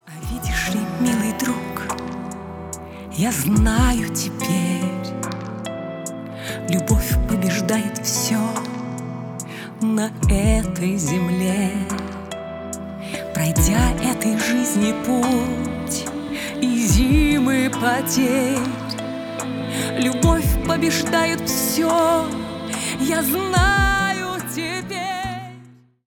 Поп Музыка
тихие # спокойные